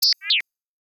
Holographic UI Sounds 91.wav